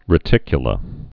(rĭ-tĭkyə-lə)